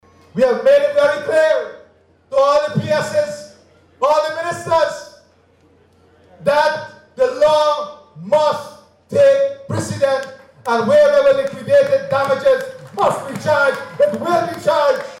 This declaration came during his speech at a memorial event for the late former President Dr. Cheddi Jagan at Babu Jaan over the weekend.